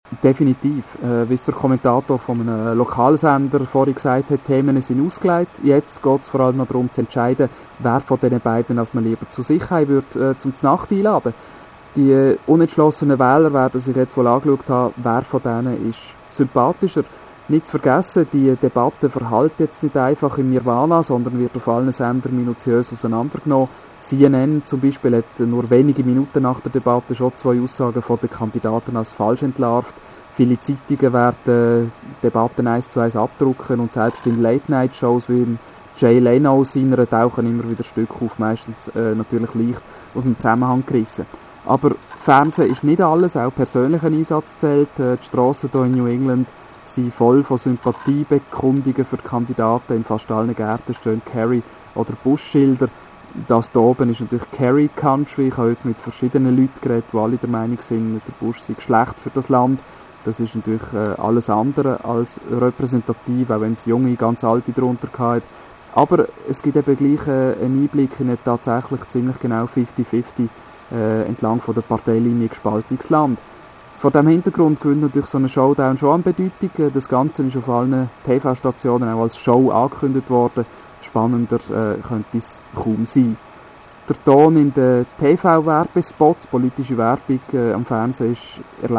Und dann also die Final Presidential Debate - verfolgt in Bennington, Vermont. meine Eindrücke gleich im O-Ton, so wie ich sie Radio ExtraBern geschildert habe:
Diese Quotes wurden natürlich auseinandergeschnipselt, das wäre alles viiiel zu viel.